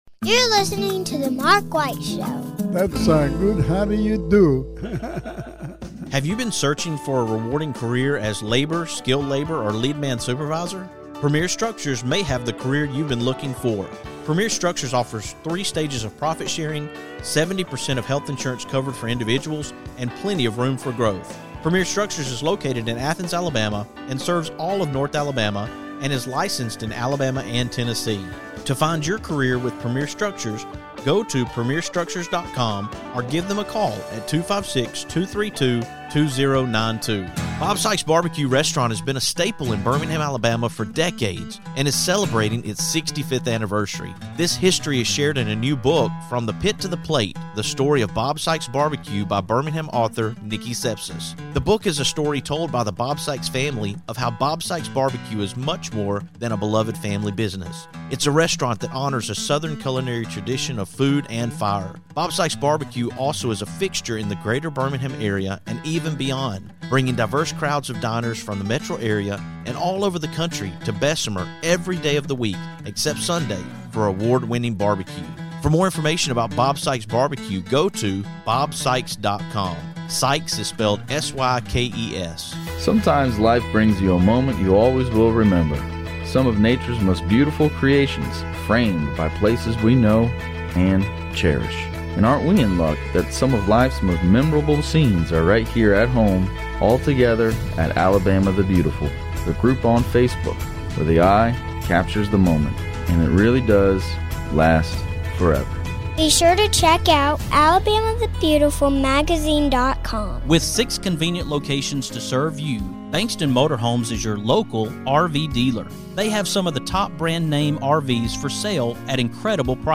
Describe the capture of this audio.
we’re coming to you from the Cullman County Public Library System as we talk bees & honey